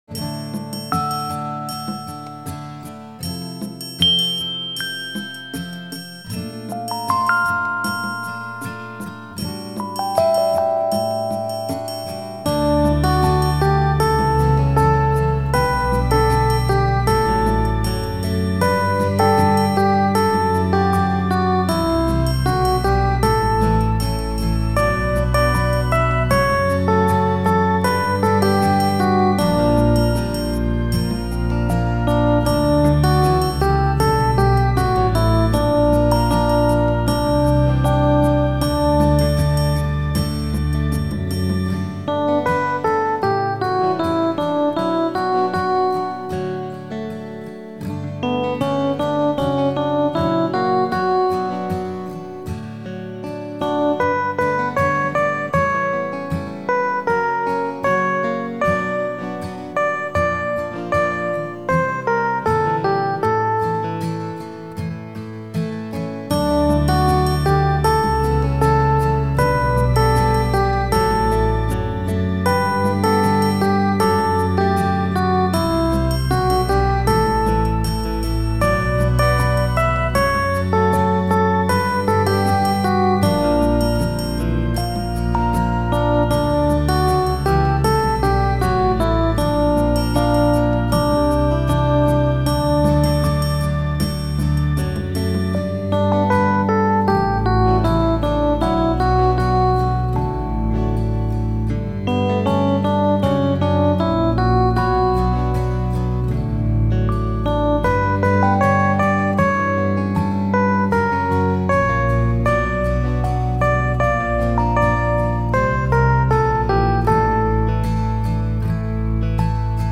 This is my backing for educational purposes only.